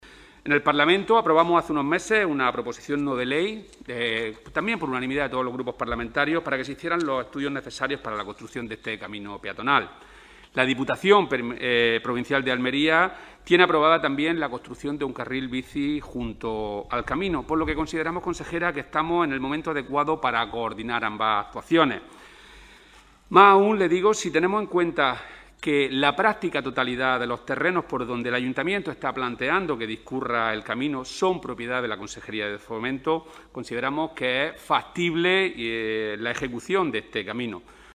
Así se lo ha pedido el diputado autonómico del PSOE de Almería Rodrigo Sánchez a la consejera Marifrán Carazo, durante la sesión de la Comisión de Fomento que se ha celebrado esta mañana en el Parlamento de Andalucía.